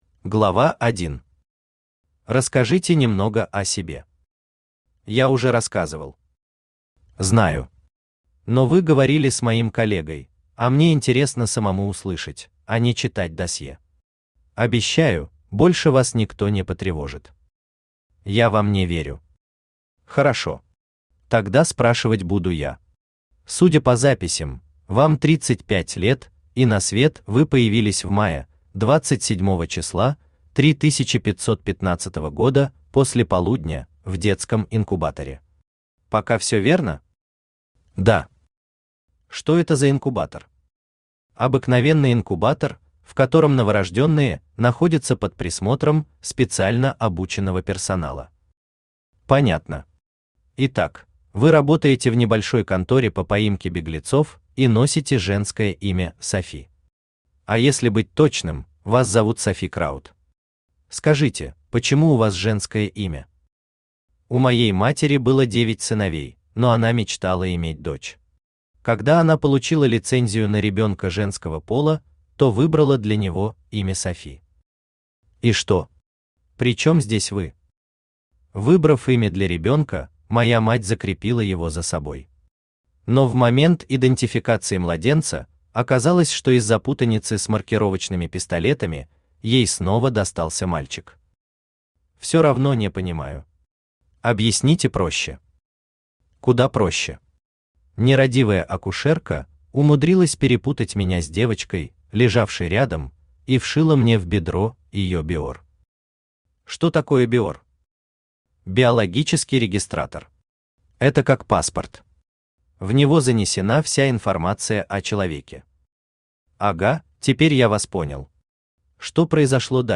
Аудиокнига Нет пути назад | Библиотека аудиокниг
Aудиокнига Нет пути назад Автор Ник Алнек Читает аудиокнигу Авточтец ЛитРес.